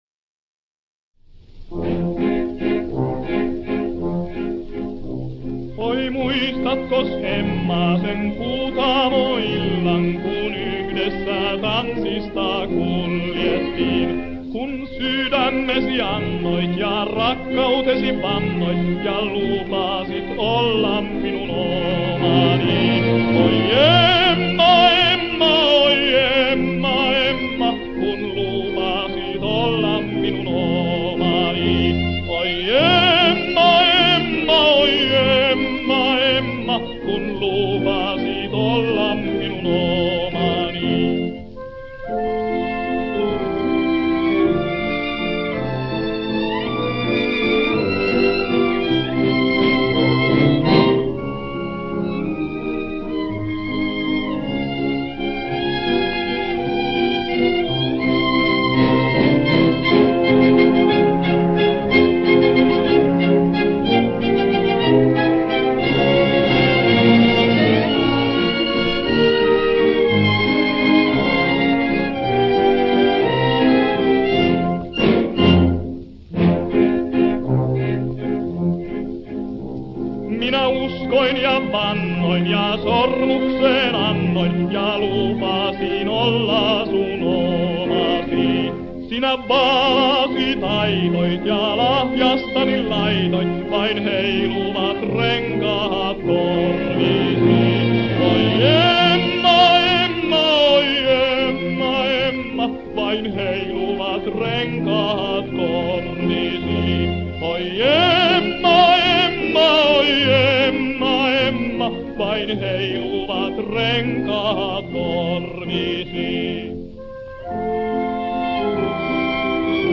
Ture Ara, der von der Oper zum Schlager gewechselt war, nahm die Volkskomposition "Emma" (